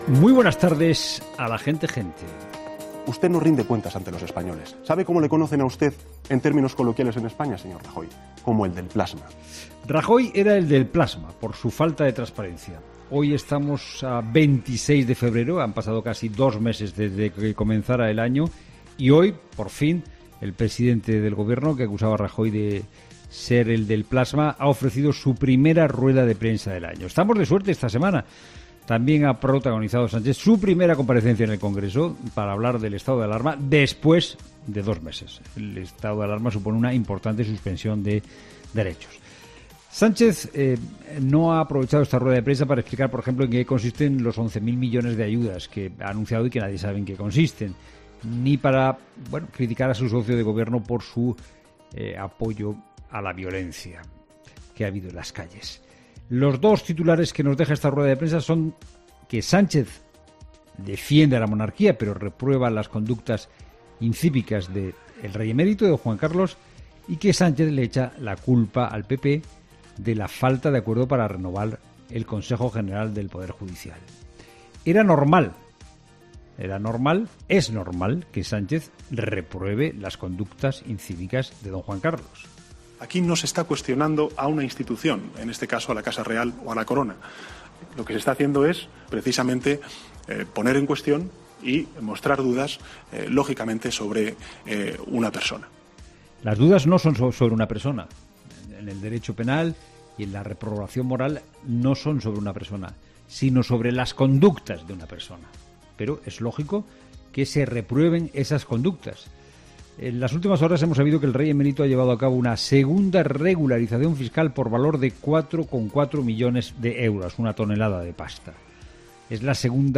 Monólogo de Fernando de Haro
El copresentador de 'La Tarde', Fernando de Haro, analiza las últimas declaraciones de Sánchez ante la opinión pública